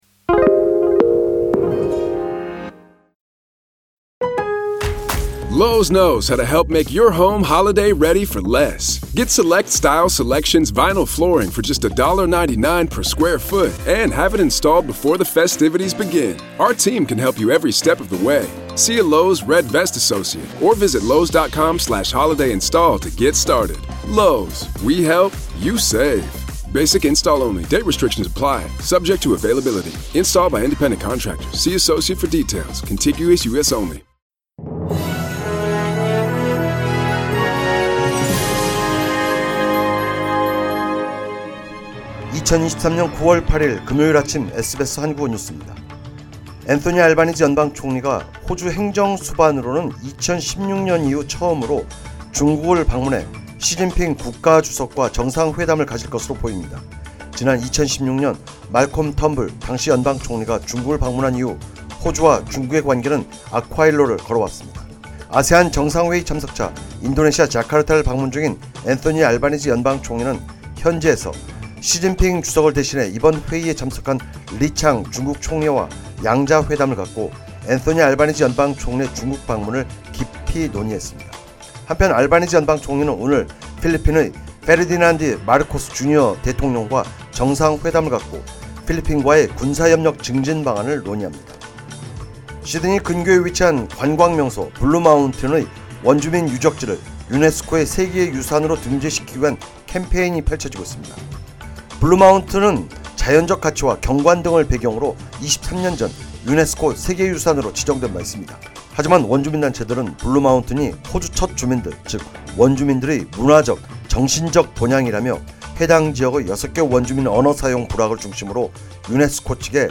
2023년 9월 8일 금요일 아침 SBS 한국어 뉴스입니다.